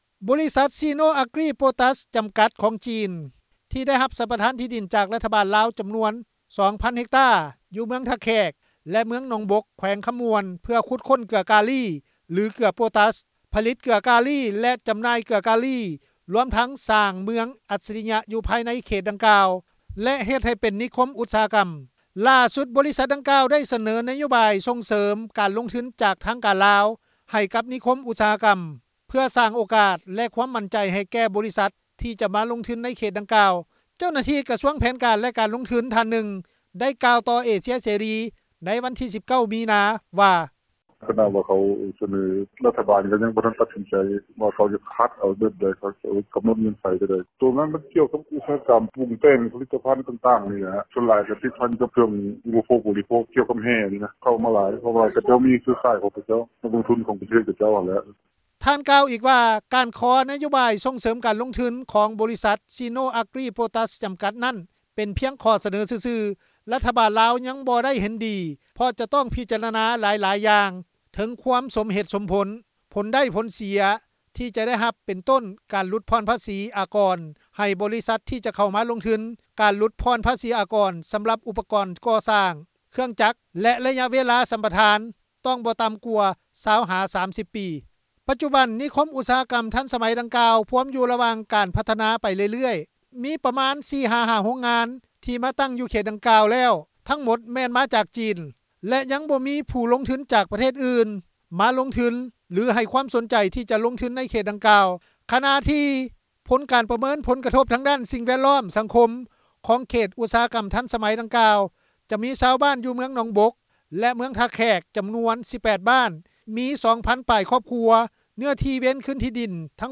ເຈົ້າໜ້າທີ່ກະຊວງແຜນການ ແລະ ການລົງທຶນ ທ່ານໜຶ່ງ ໄດ້ກ່າວຕໍ່ເອເຊັຽເສຣີ ໃນວັນທີ 19 ມີນາ ວ່າ:
ຊາວເມືອງໜອງບົກ ໄດ້ກ່າວຕໍ່ເອເຊັຽເສຣີ ໃນວັນທີ 19 ມີນາ ວ່າ: